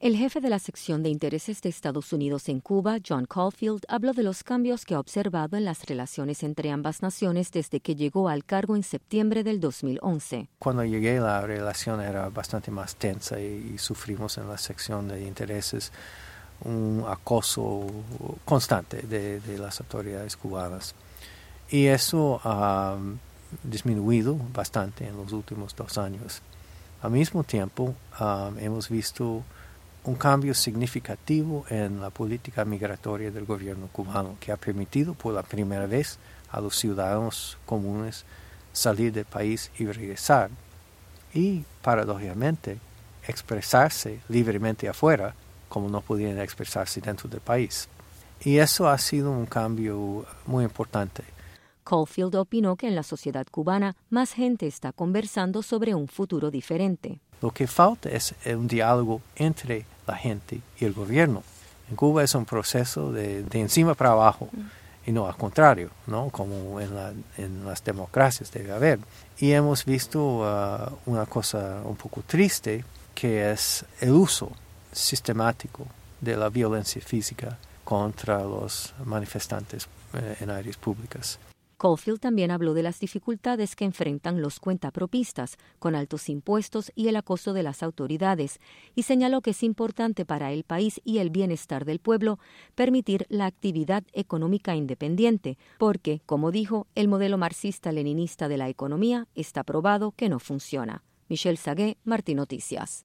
Entrevista al Jefe de la Sección de Intereses de EEUU en Cuba.
En entrevista con Radio Martí, el jefe de la Sección de Intereses de Estados Unidos en Cuba, John Caulfield, repasó las relaciones entre ambos países durante su período en el cargo, que culminará el próximo mes de julio.